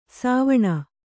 ♪ sāvaṇa